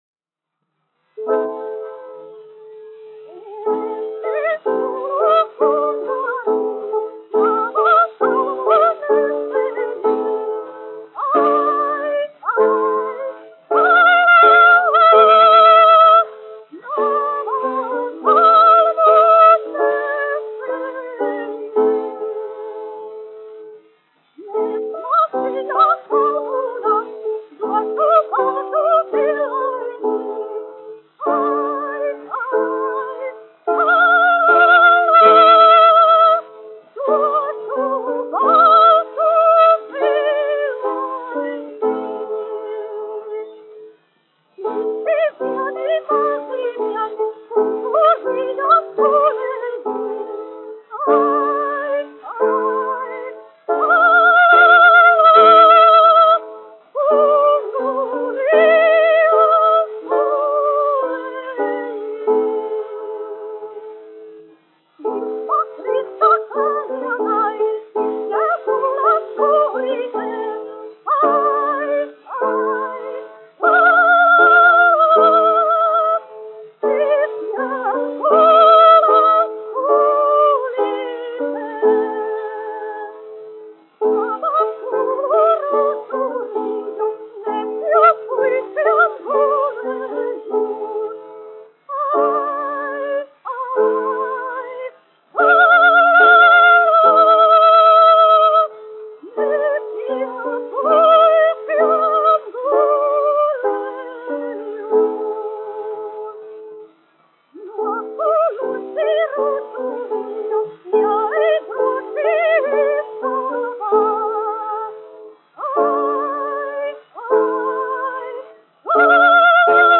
Es izkūlu kunga riju : ar klavieru pavadījumu
dziedātājs
1 skpl. : analogs, 78 apgr/min, mono ; 25 cm
Latviešu tautasdziesmas
Skaņuplate